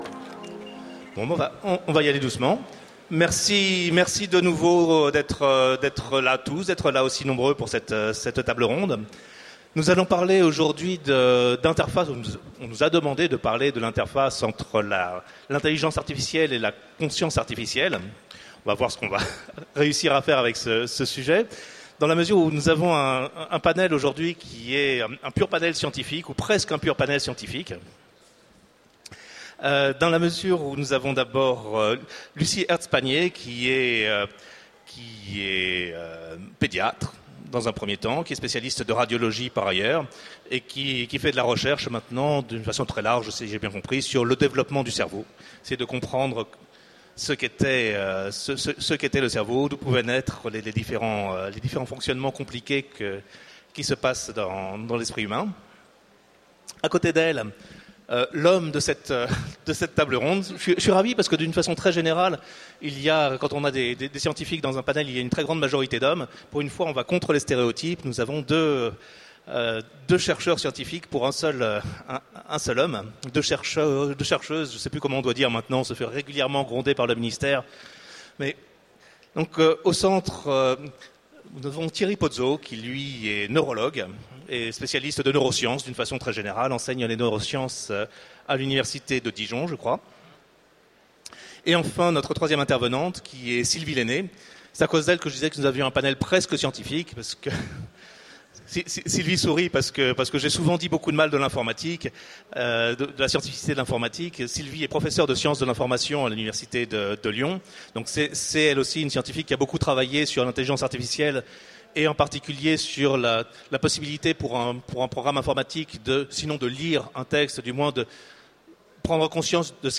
Mots-clés Intelligence artificielle Conférence Partager cet article